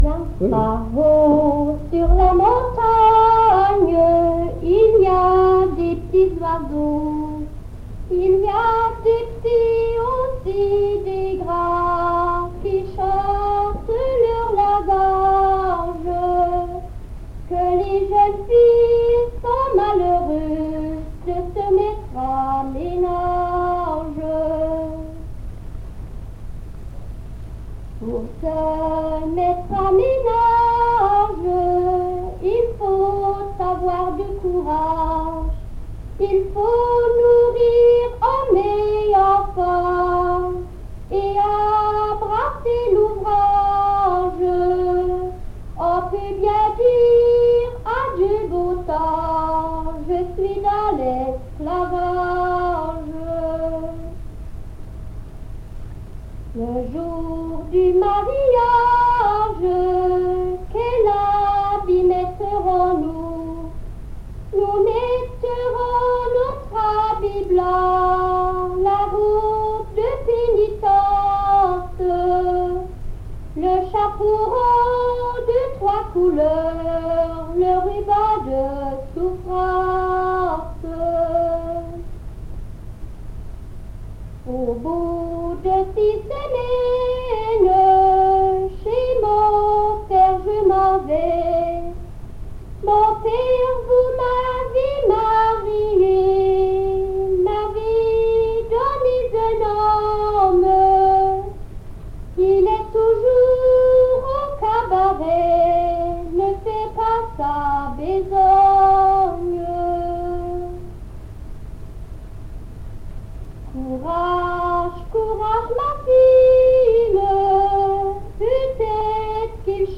Genre : chant
Type : chanson narrative ou de divertissement
Aire culturelle d'origine : Haute Ardenne
Lieu d'enregistrement : Waimes
Support : bande magnétique
Chanson incomplète.